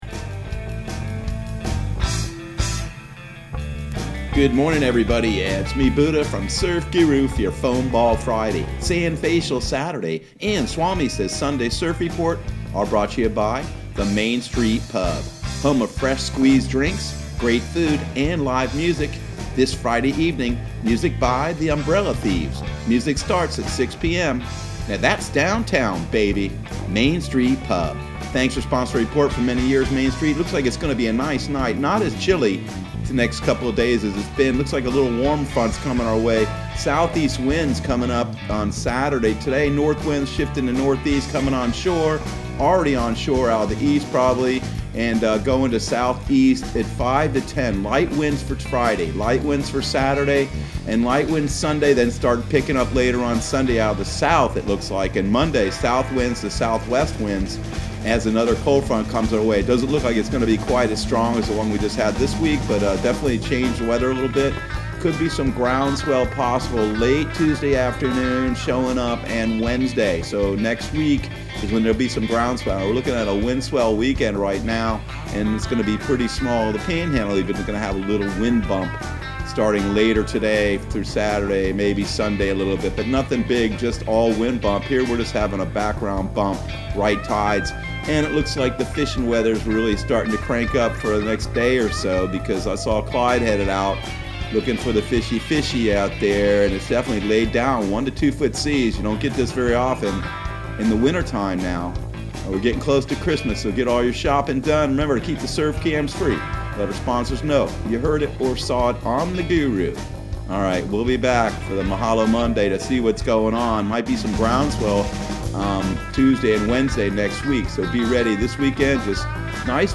Surf Guru Surf Report and Forecast 12/11/2020 Audio surf report and surf forecast on December 11 for Central Florida and the Southeast.